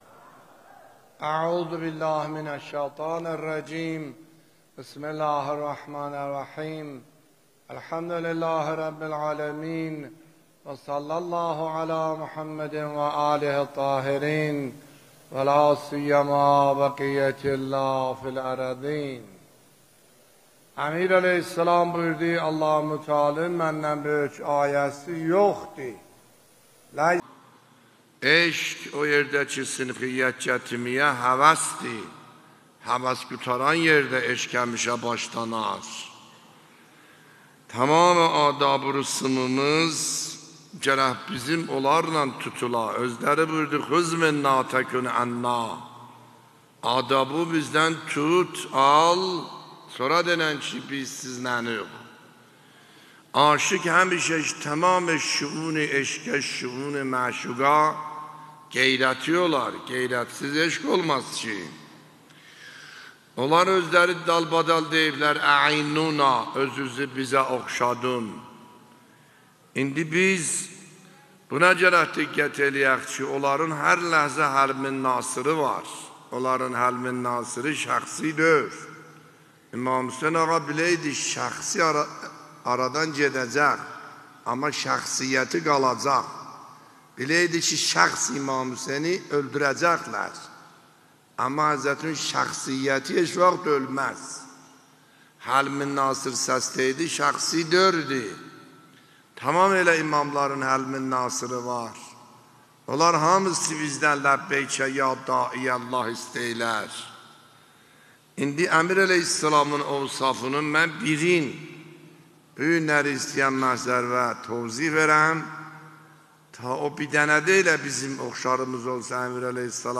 دانلود و پخش آنلاین سخنرانی آیت الله سید حسن عاملی در شب بیست و یکمین روز ماه مبارک رمضان 1402 در مسجد میرزا علی اکبر